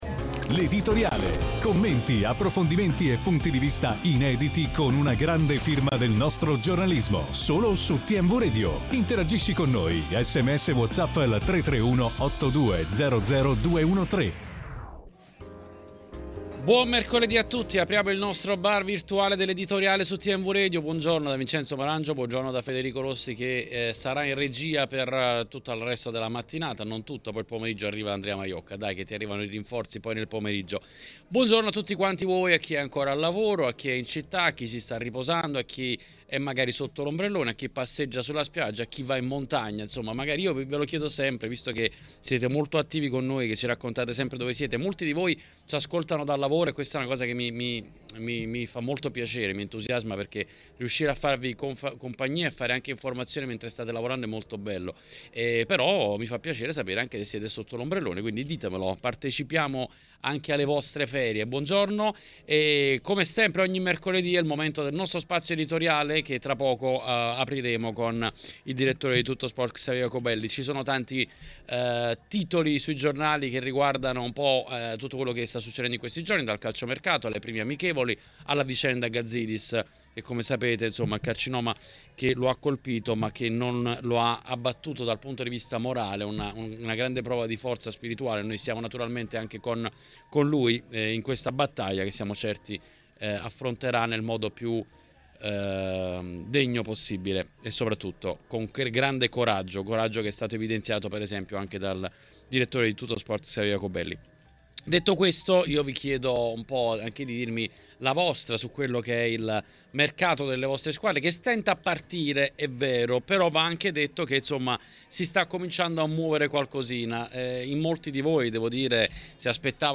L'editoriale
in diretta su TMW Radio: